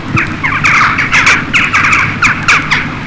Choucas (bird) Sound Effect
Download a high-quality choucas (bird) sound effect.
choucas-bird.mp3